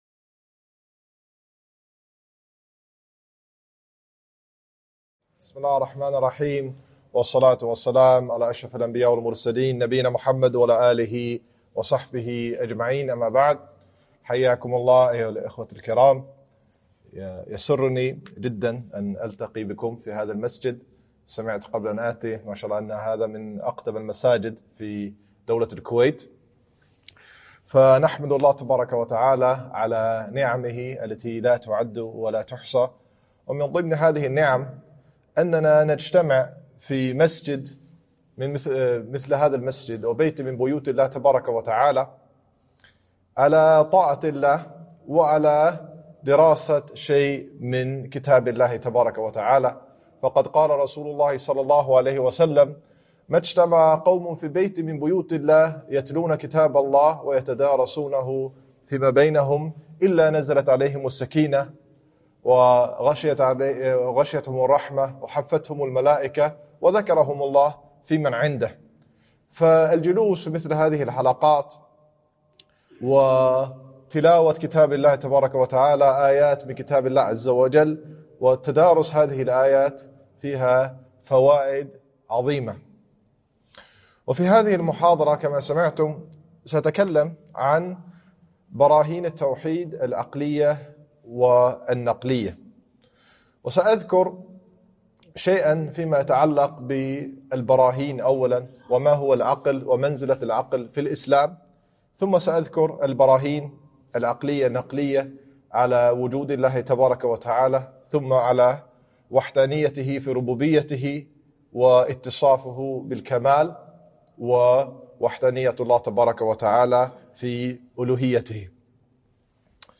محاضرة - براهين التوحيد النقلية والعقلية